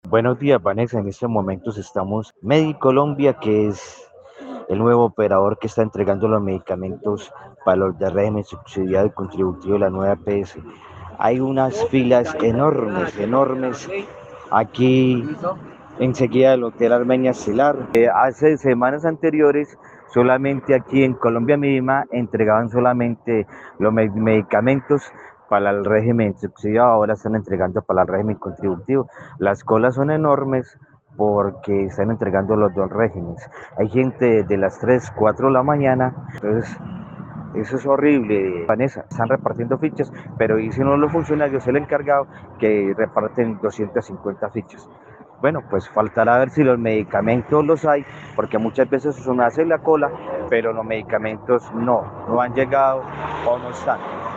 Usuario de la Nueva EPS